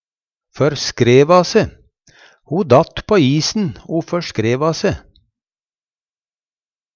førskreva se - Numedalsmål (en-US)
Hør på dette ordet Ordklasse: Uttrykk Kategori: Kropp, helse, slekt (mennesket) Karakteristikk Attende til søk